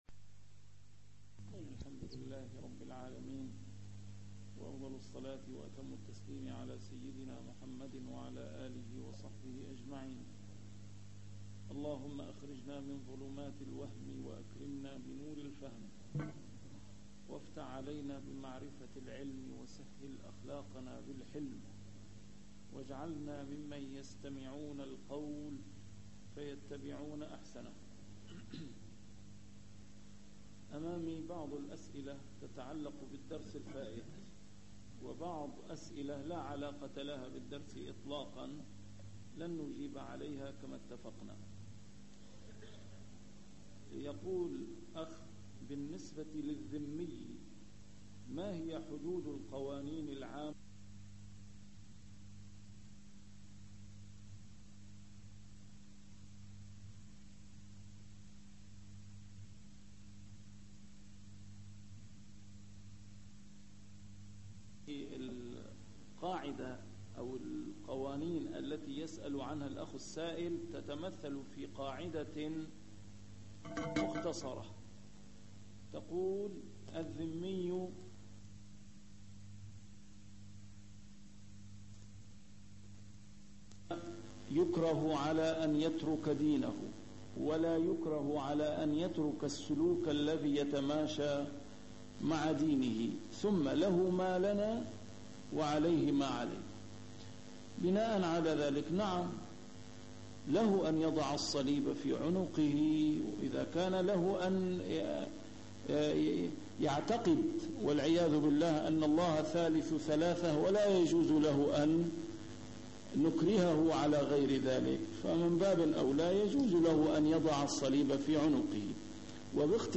شرح الأحاديث الأربعين النووية - A MARTYR SCHOLAR: IMAM MUHAMMAD SAEED RAMADAN AL-BOUTI - الدروس العلمية - علوم الحديث الشريف - تتمة شرح الحديث السادس والثلاثون: حديث أبي هريرة (مَنْ نَفَّسَ عن مؤمنٍ كربة) 121